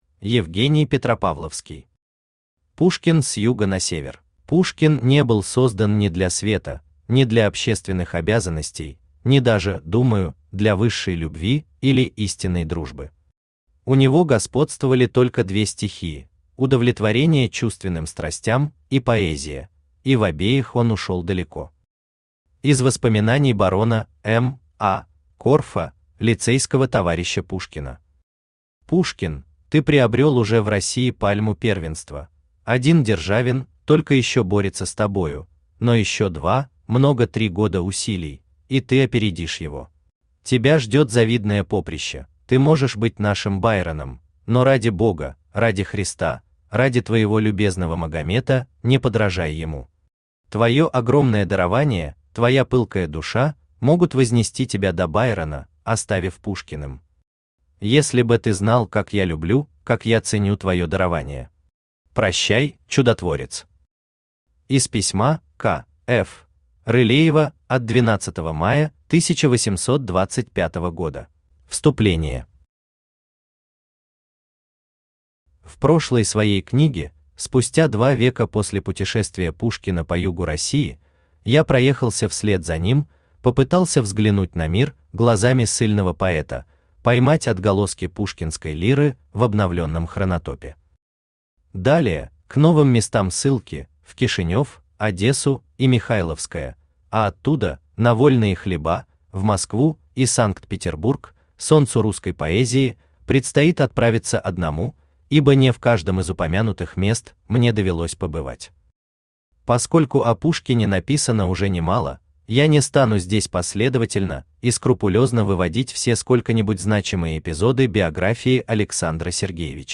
Аудиокнига Пушкин с юга на север | Библиотека аудиокниг
Aудиокнига Пушкин с юга на север Автор Евгений Петропавловский Читает аудиокнигу Авточтец ЛитРес.